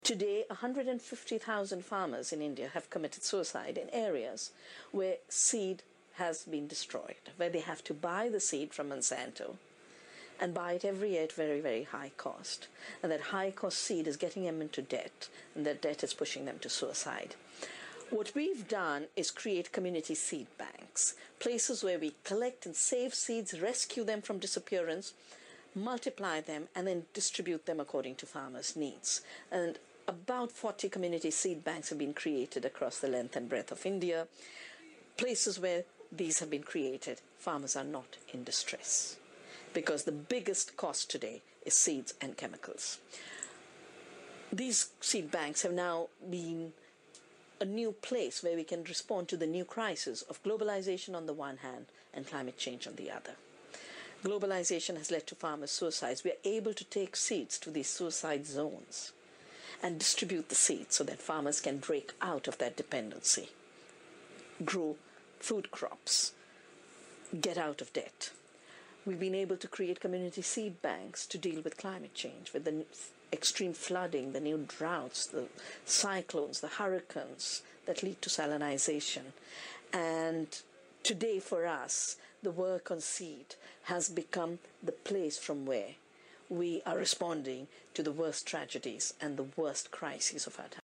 You will hear an Interview/Lecture.